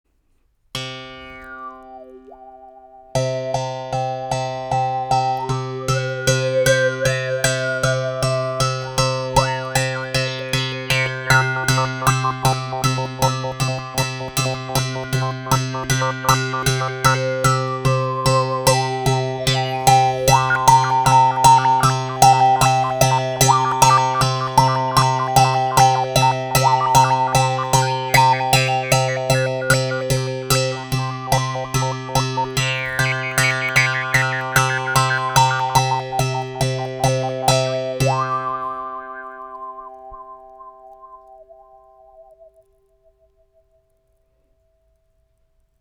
ARC EN BOUCHE 1 CORDE
Les sons proposés ici sont réalisés sans effet.
La baguette sera utilisée pour percuter la corde.